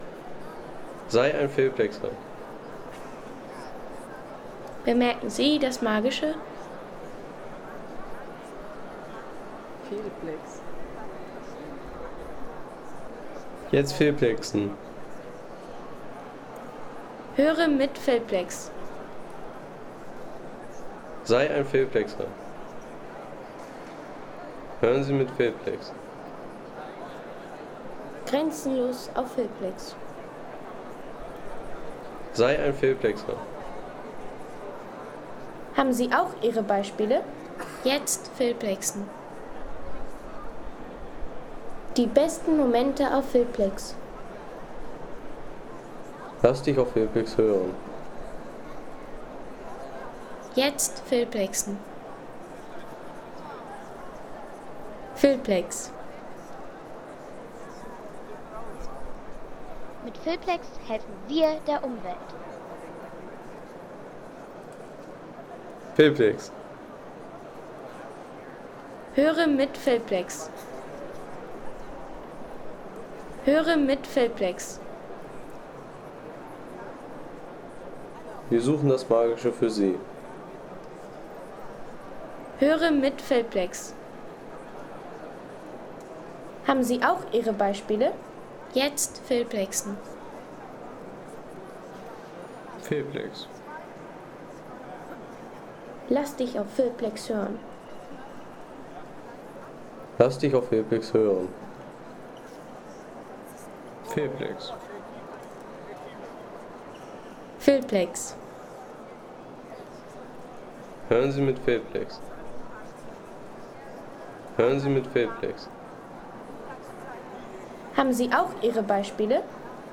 Das Pantheon in Rom – Die beeindruckende Akustik.